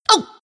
Worms speechbanks
Ooff1.wav